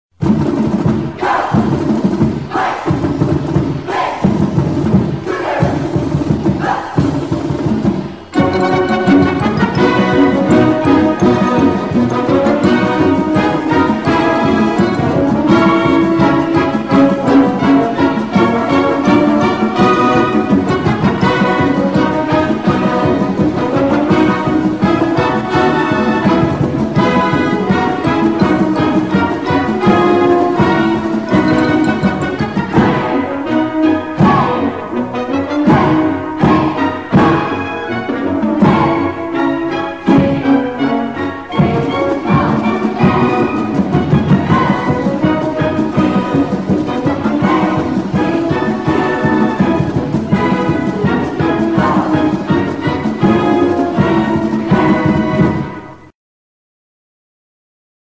Butch the Cougar cartoon with sousaphoneListen to the Cougar Marching Band playing
WSU Fight Song (live recordings).